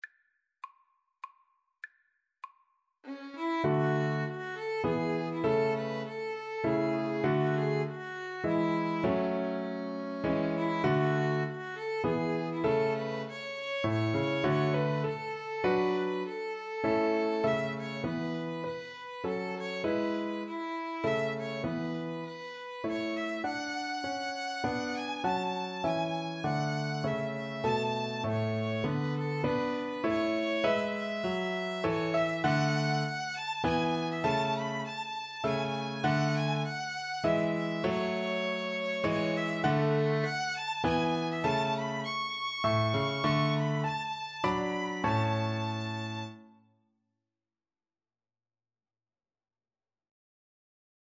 Violin-Viola Duet version
(with piano)
ViolinViolaPiano
3/4 (View more 3/4 Music)
Classical (View more Classical Violin-Viola Duet Music)